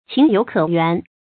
情有可原 注音： ㄑㄧㄥˊ ㄧㄡˇ ㄎㄜˇ ㄧㄨㄢˊ 讀音讀法： 意思解釋： 原：原諒。從情理上說；有可以原諒的地方。